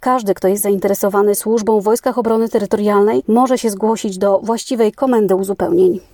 Mówi